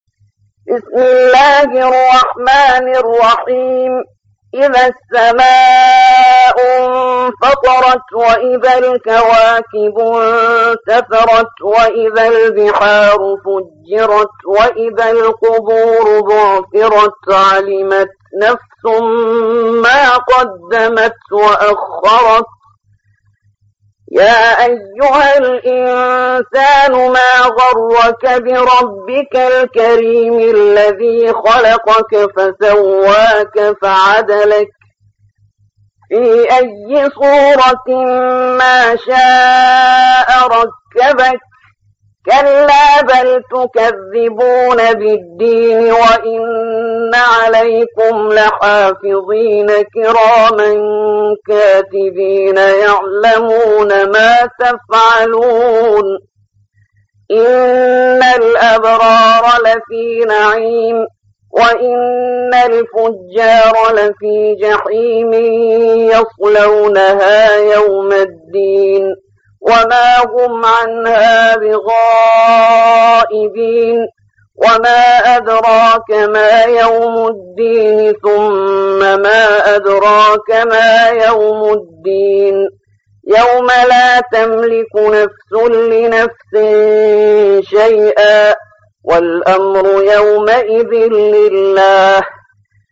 82. سورة الانفطار / القارئ